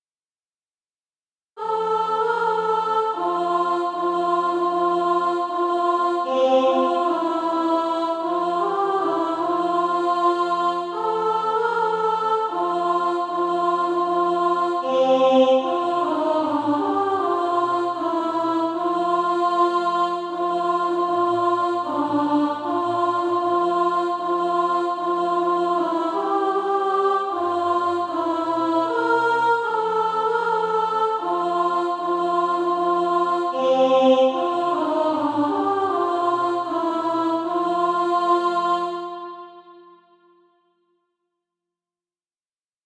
(SATB) Author
Practice then with the Chord quietly in the background.